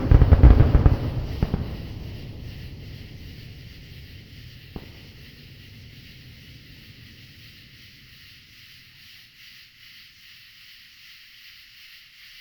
I recorded the Sewanee annual firework display at a lookout spot across a mountain cove from the detonations. Before the pyrotechnics, the katydids made my ears ring, but the explosions out-shouted even the combined acoustic power of tens of thousands of singing insects.
Here is the finale of the show:
If you listen with earphones, you’ll hear the low-frequency roar of the fireworks rushing down through the mountain cove for five long seconds after the blast. From where I sat at the Proctor’s Hall rock, the echo ran from right to left, flowing down, like a dark bird launching from the cliffs to the fields below the cove.
firewordforest.mp3